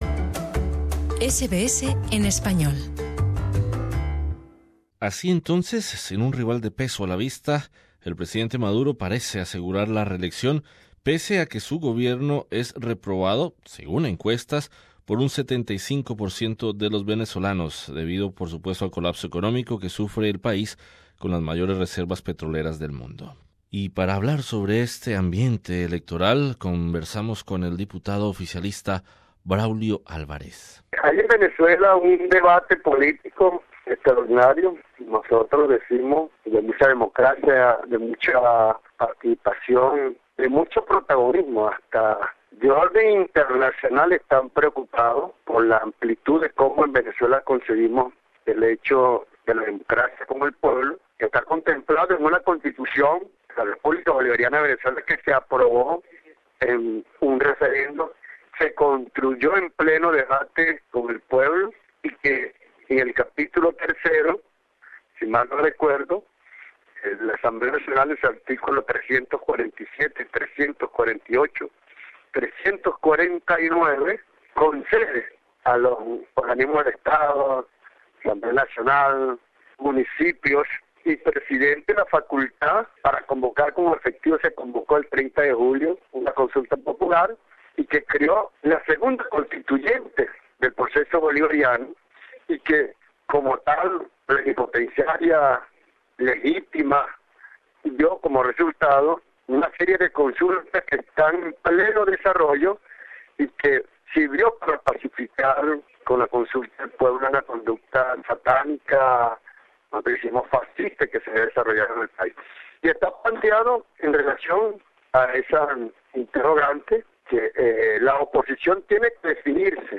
Entrevistados: Diputado oficialista Braulio Alvarez